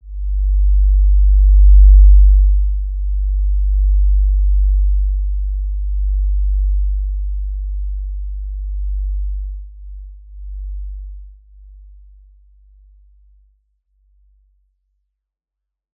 Basic-Tone-G1-mf.wav